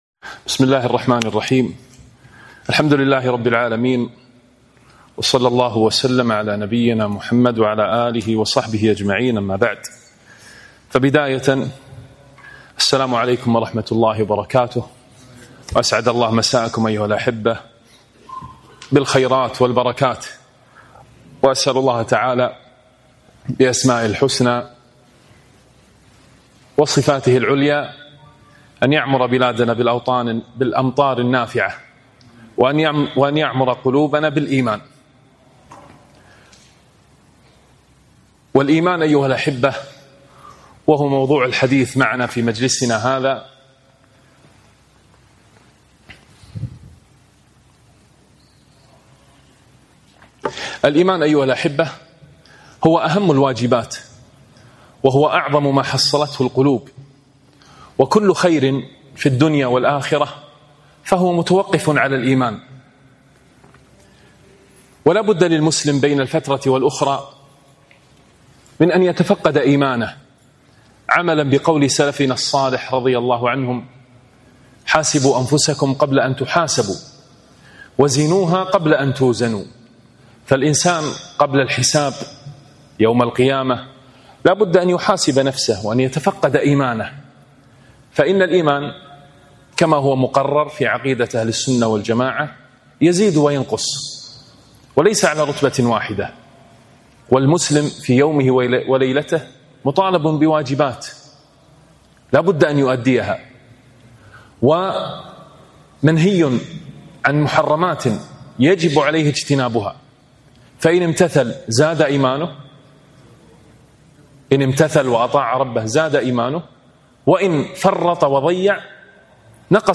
محاضرة - الإيمان بين الزيادة والنقصان